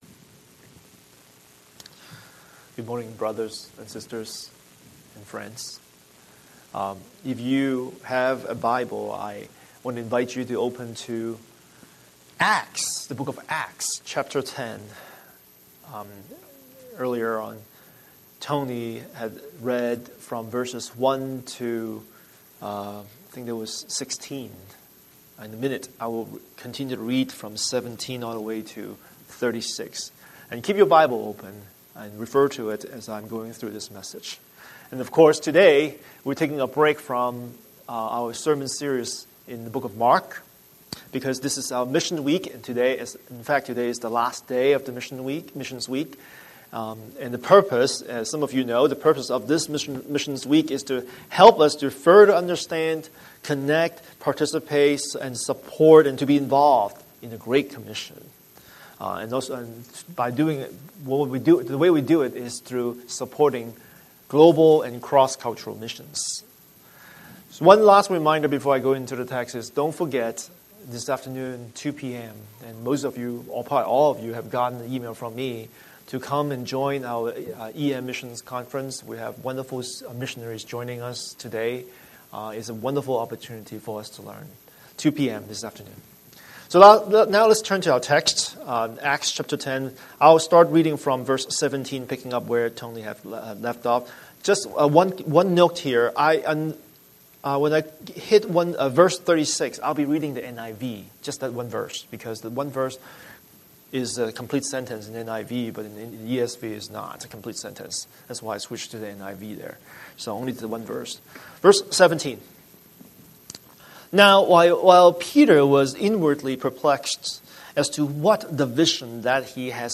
Scripture: Acts 10:1-16 Series: Sunday Sermon